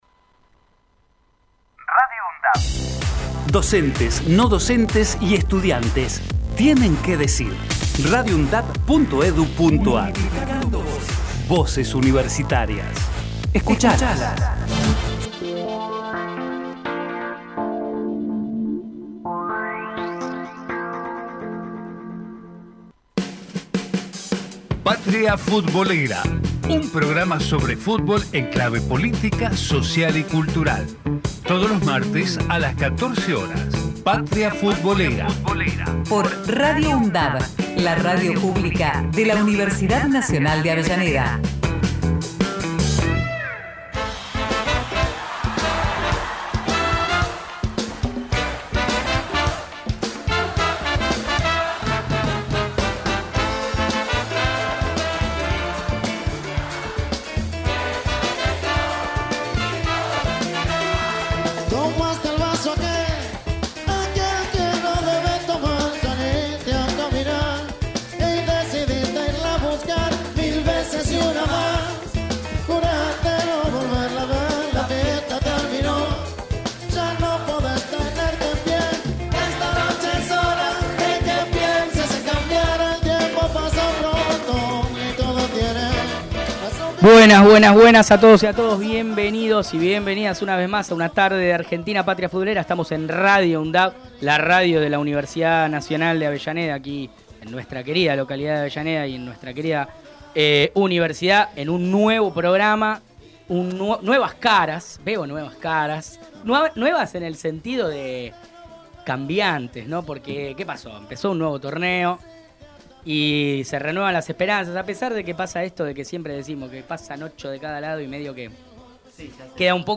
Patria Futbolera Texto de la nota: Un programa sobre fútbol en clave política, social y cultural.